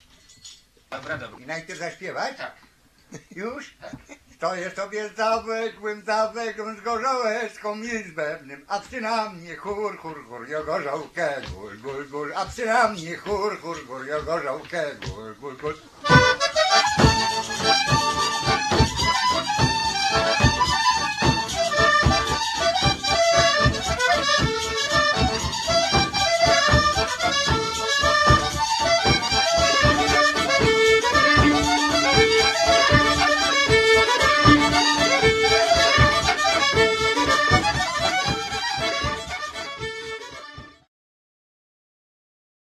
Mazurek "Stoję sobie za węgłem" (Cukrówka, 1986)
harmonia pedałowa 3-rzędowa, 80-basowa "Ostrowski"
skrzypce
bębenek